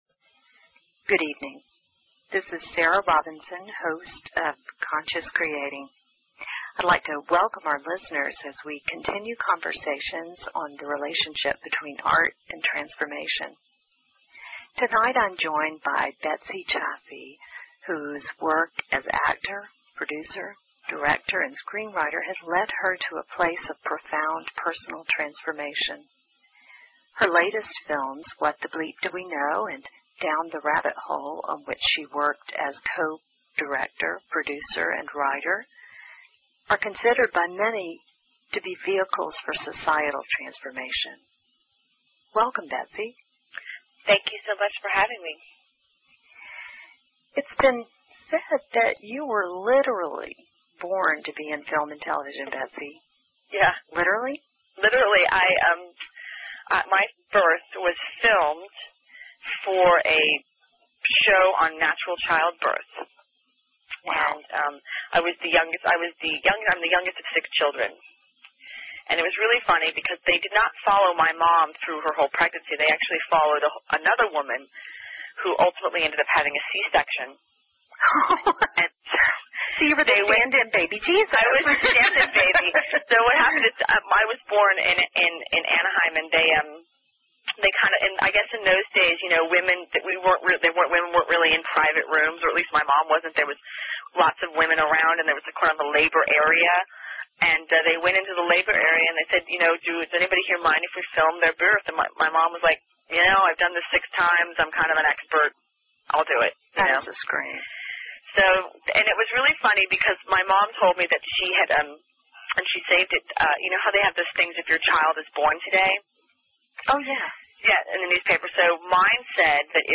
Talk Show Episode, Audio Podcast, Conscious Creating and Courtesy of BBS Radio on , show guests , about , categorized as